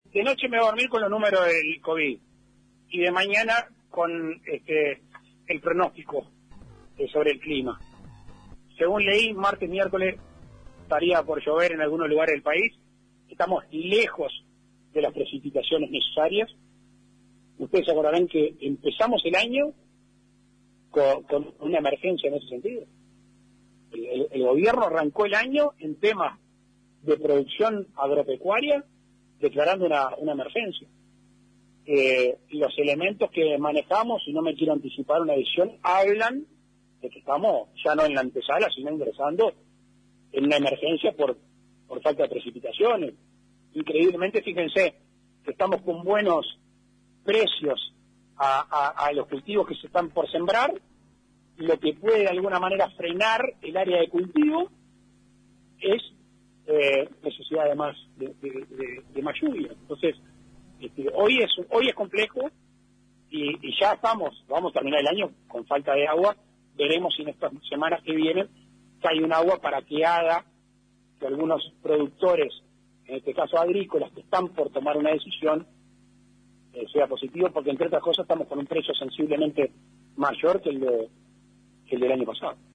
En su visita a Melo, Cerro Largo, el Presidente de la República, Lacalle Pou fue consultado sobre el déficit hídrico que afecta a varias zonas y productores del Uruguay.